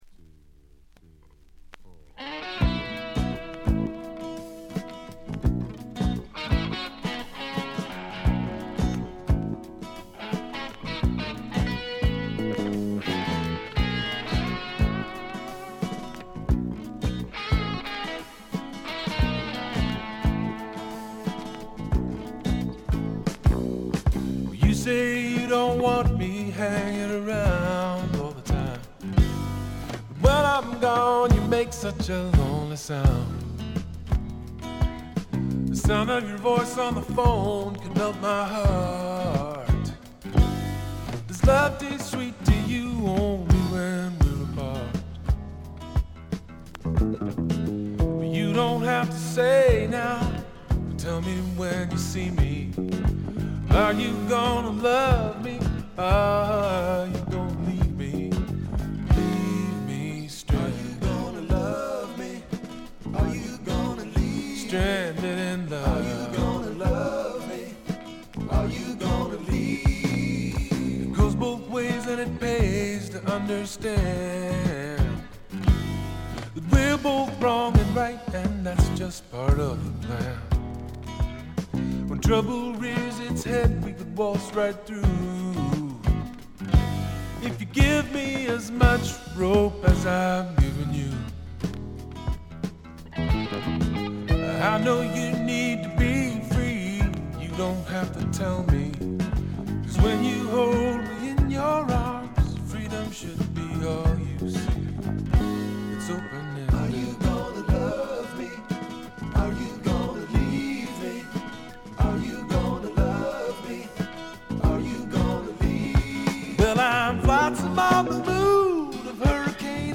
静音部で軽微なチリプチ。
メロウ系、AOR系シンガー・ソングライターのずばり名作！
試聴曲は現品からの取り込み音源です。